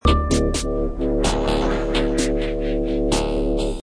Descarga de Sonidos mp3 Gratis: ritmo 16.